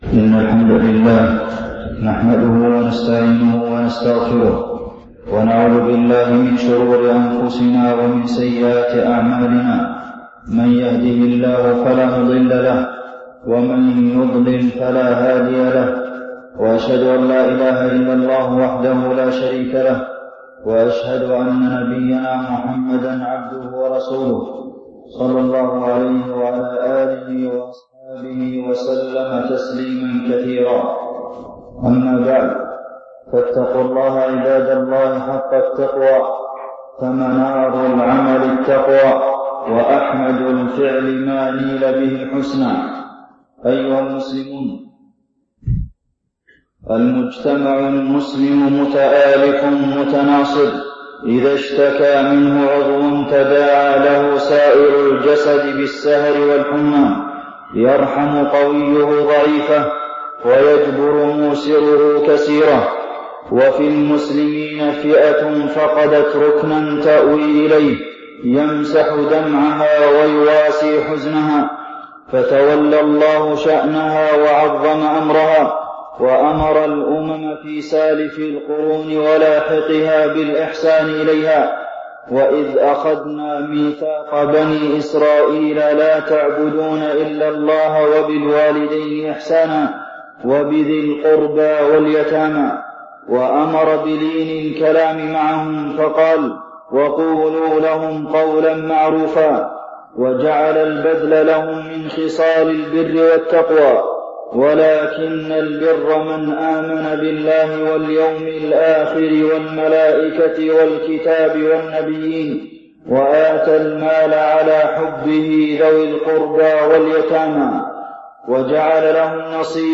تاريخ النشر ١١ شعبان ١٤٢٨ هـ المكان: المسجد النبوي الشيخ: فضيلة الشيخ د. عبدالمحسن بن محمد القاسم فضيلة الشيخ د. عبدالمحسن بن محمد القاسم حقوق اليتيم